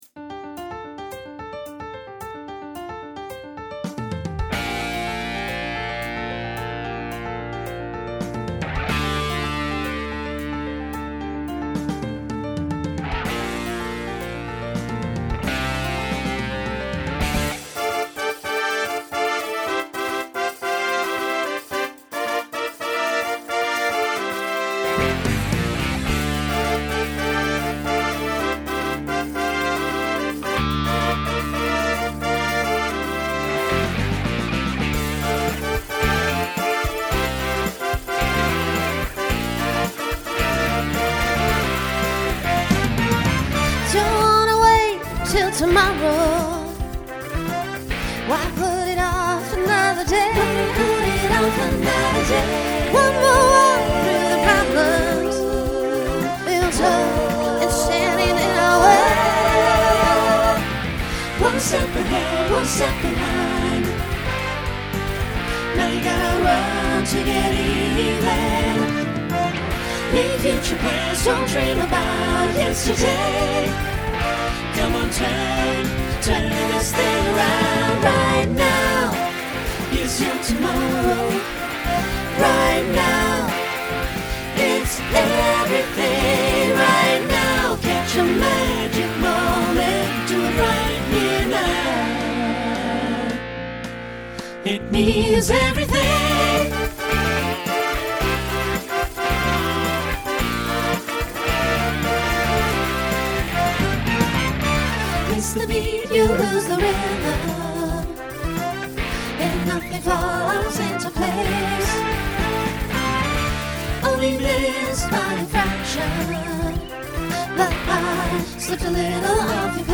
Voicing SATB Instrumental combo Genre Rock